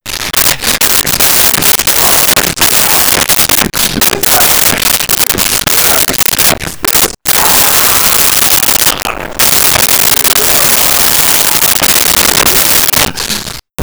Fighting Yelling Male 01
Fighting Yelling Male 01.wav